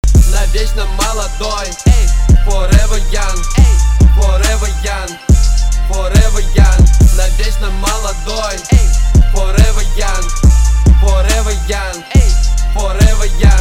русский рэп
качающие